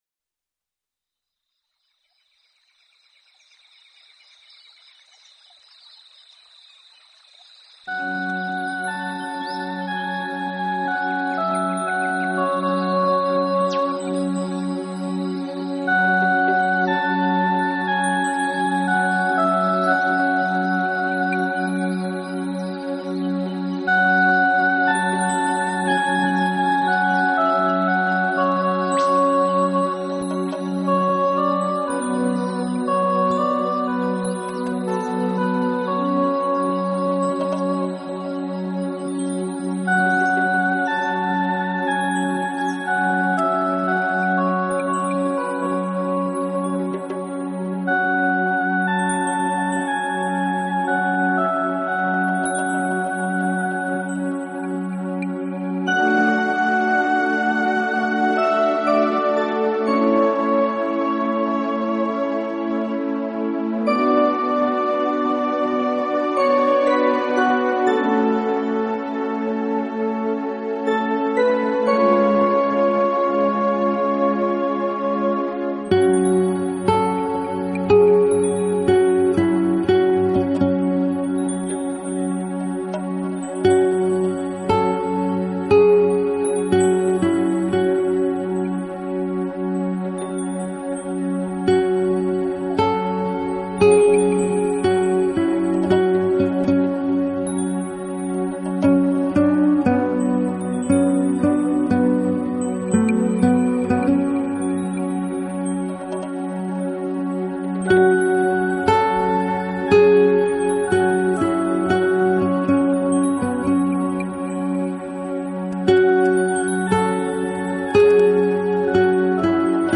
Style: Meditative, Relax